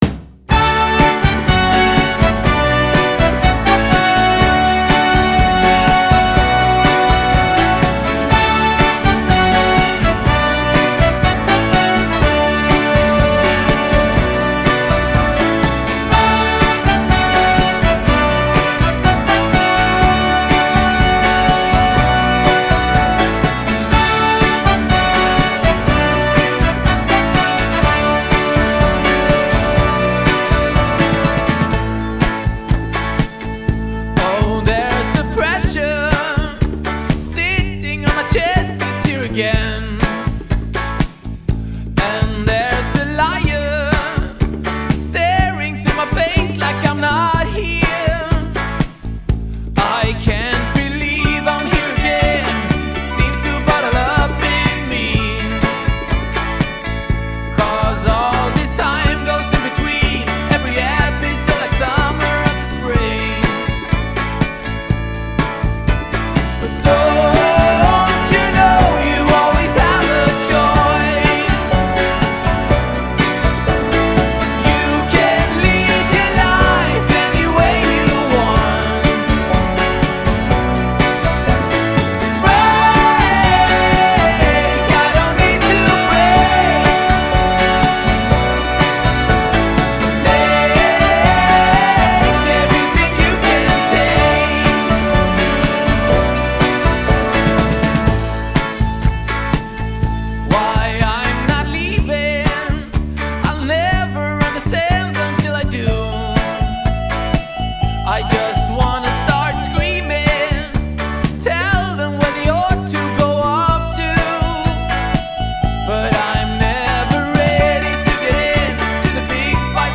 Genre: Indie-Pop / Folk